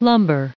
489_lumber.ogg